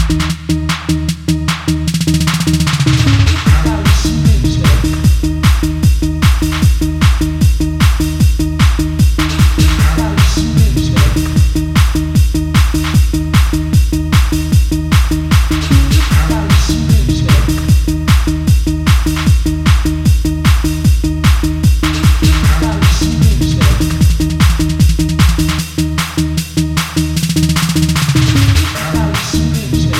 Master qualité studio disponible sur demande .